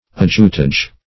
Ajutage \Aj"u*tage\, n. [F. ajutage, for ajoutage, fr. ajouter